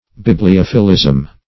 \Bib`li*oph"i*lism\